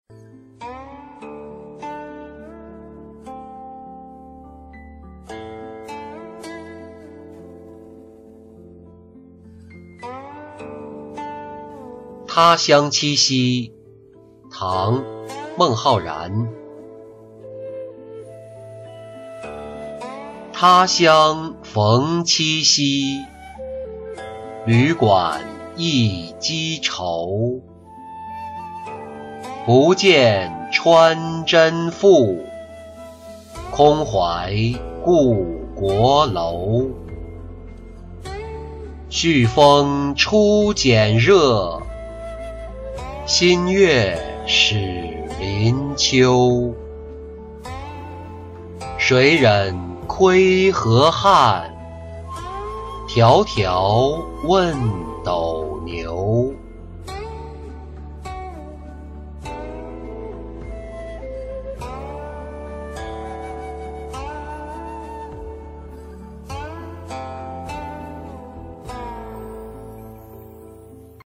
他乡七夕-音频朗读